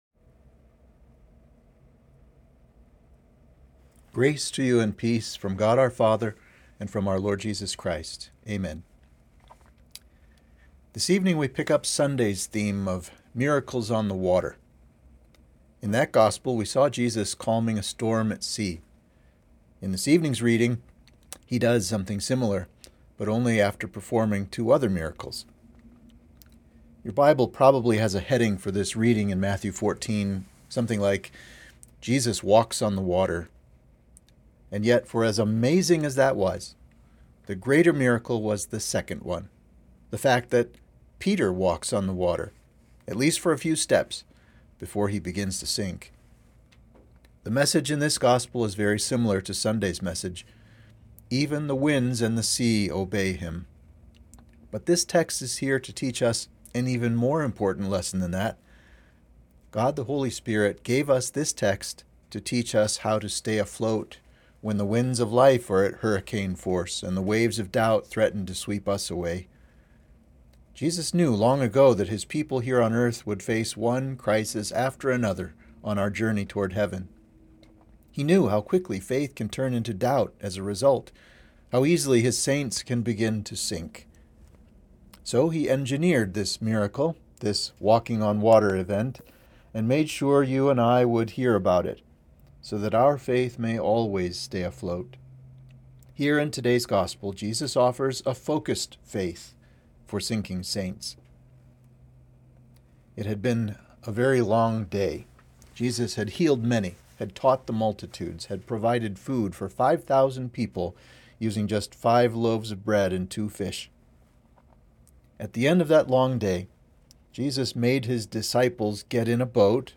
Sermon for Midweek of Epiphany 4